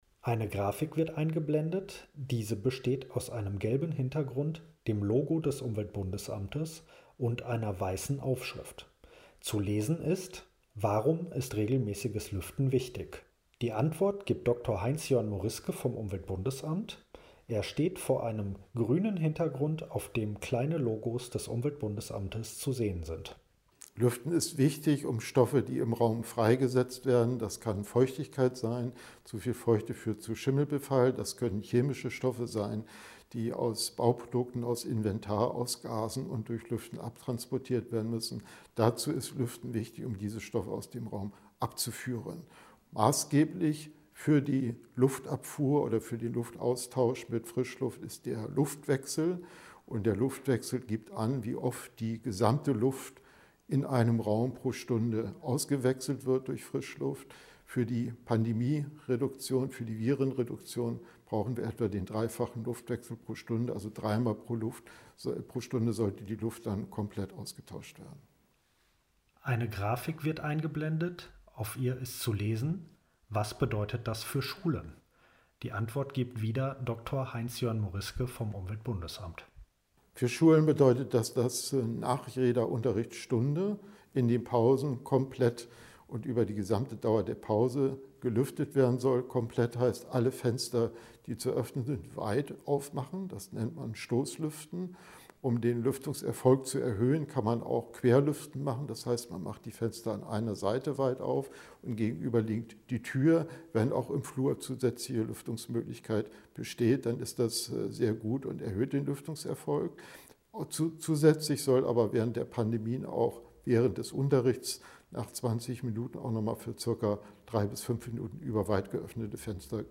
Audiodeskription für Video: Nachgefragt: Lüften in Schulen
audiodeskription_video_lueften_in_schulen.mp3